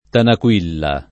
vai all'elenco alfabetico delle voci ingrandisci il carattere 100% rimpicciolisci il carattere stampa invia tramite posta elettronica codividi su Facebook Tanaquilla [ tanak U& lla ] o Tanaquil [ t # nak U il ] pers. f. stor.